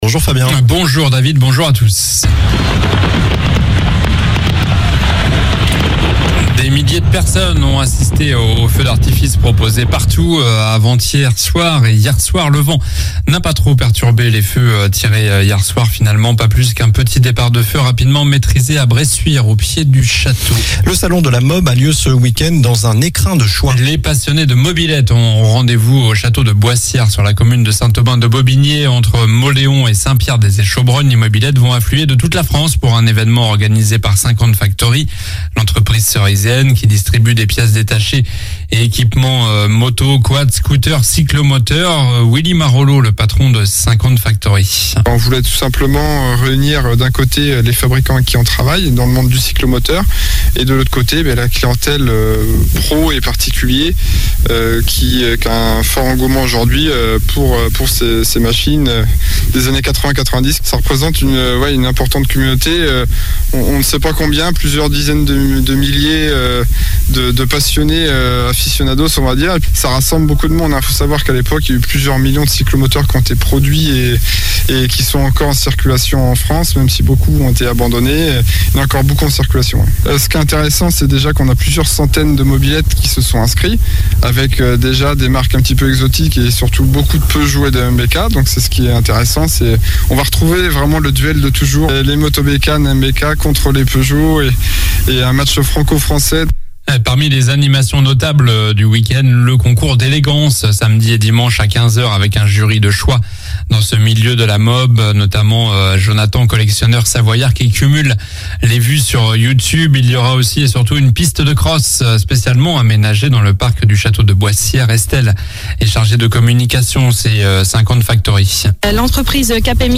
Journal du samedi 15 juillet (matin)